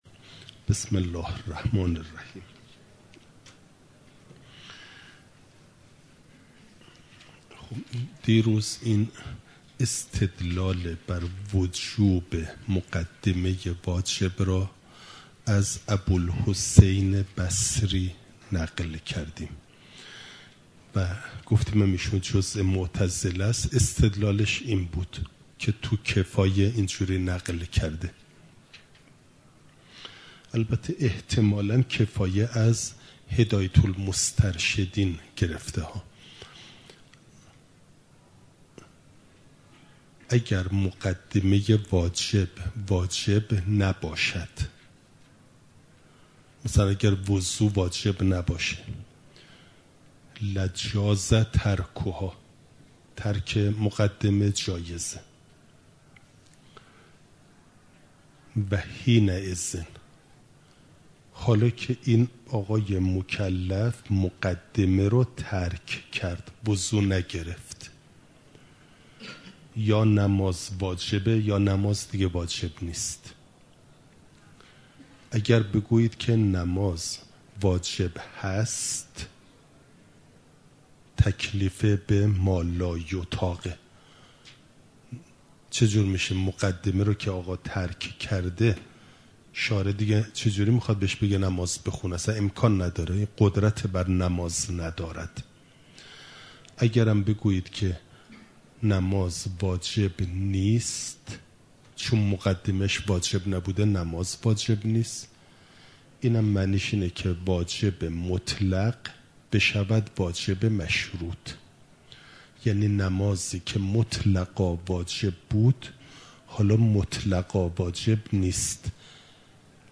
خارج اصول، مقدمه واجب (جلسه ۵۹) – دروس استاد